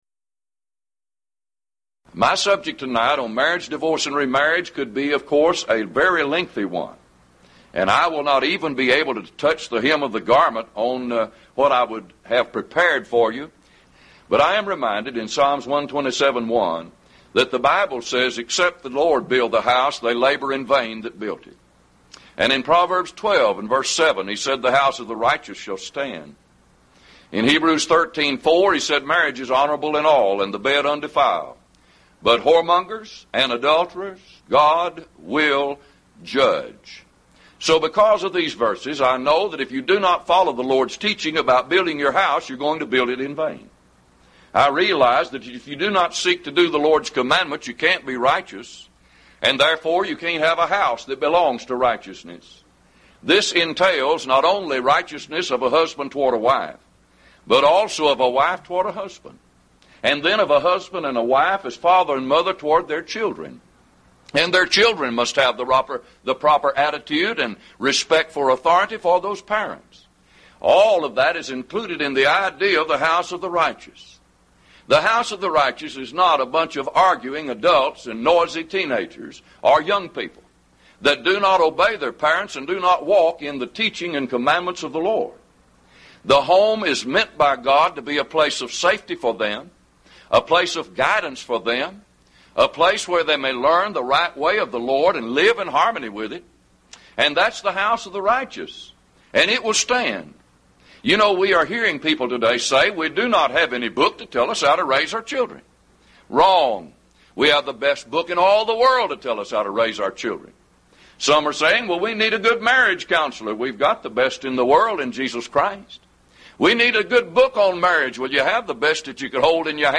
Event: 1st Annual Lubbock Lectures
If you would like to order audio or video copies of this lecture, please contact our office and reference asset: 1998Lubbock34